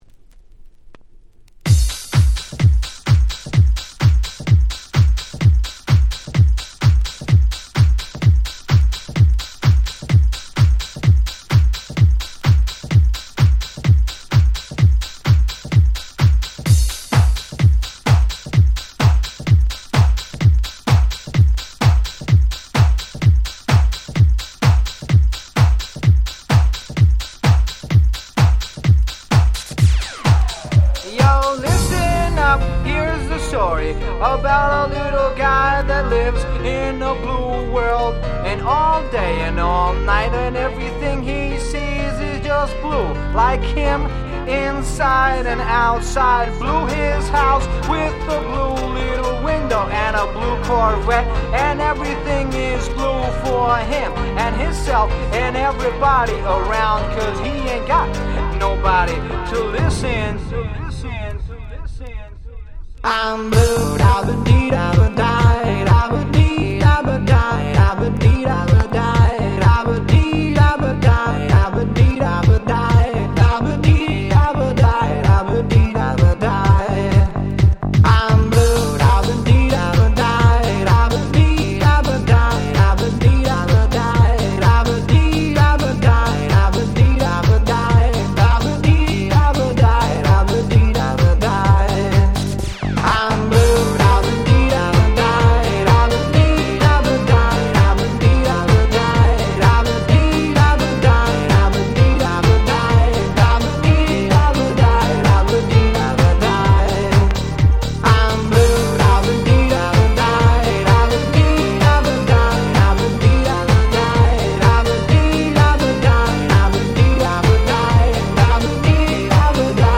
98' Euro Dance Super Classics !!